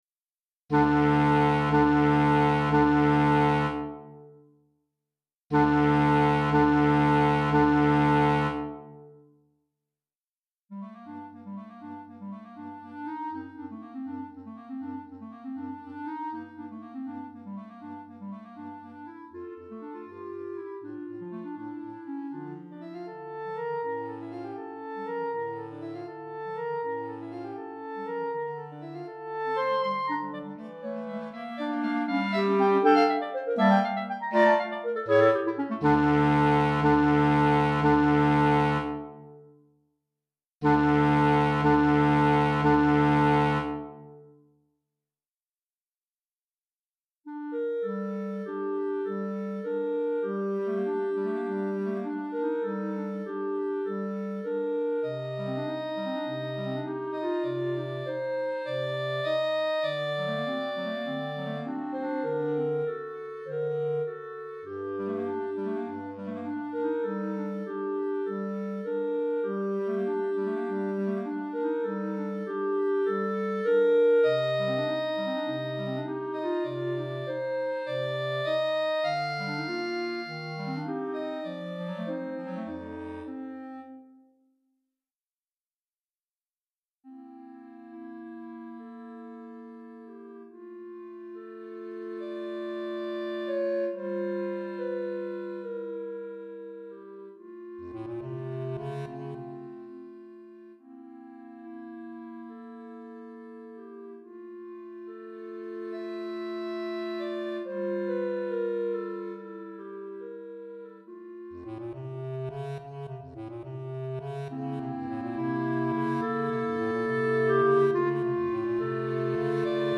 3 Clarinettes en Sib et Clarinette Basse